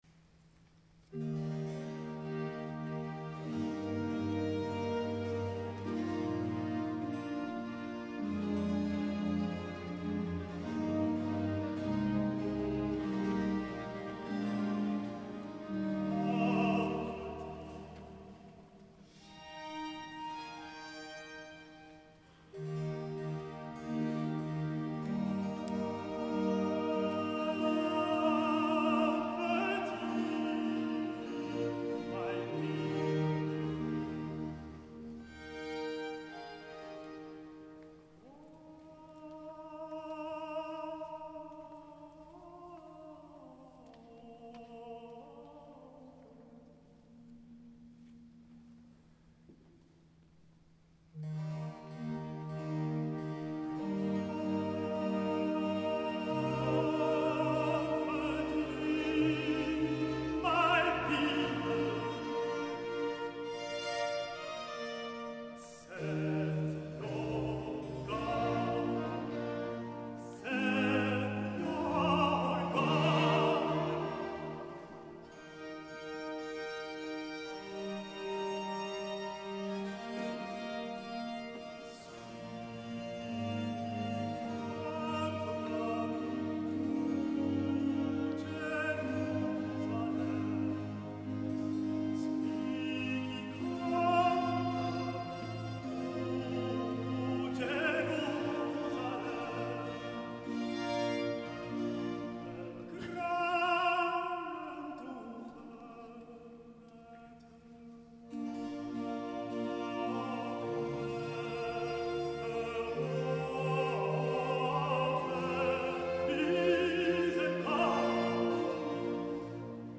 Op de audiopagina's vindt u een uitgebreide selectie van prachtige live opnames.